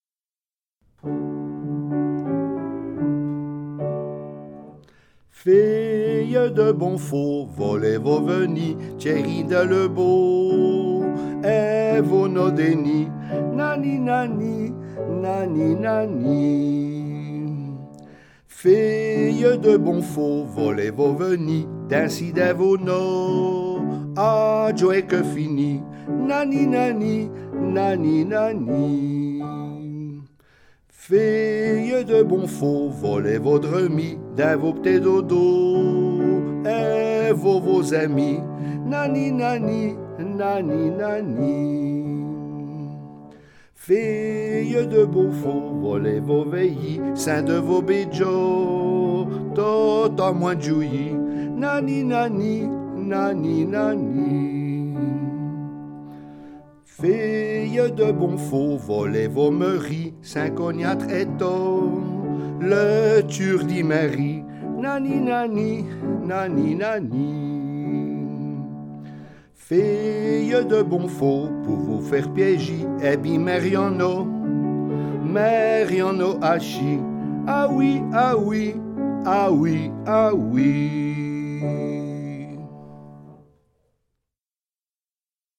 Tchainsons
Piano seul